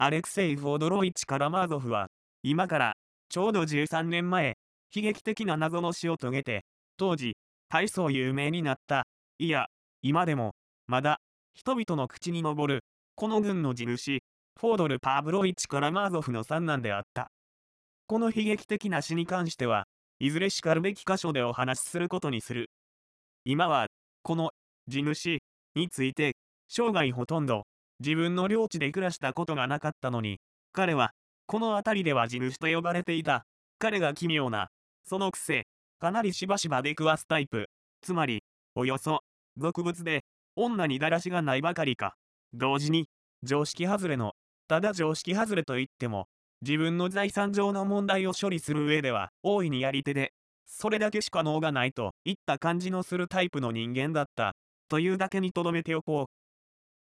電子書籍や、音声朗読機能を使ってみるのも、良さそうなので、試しに作ってみました。
女性の声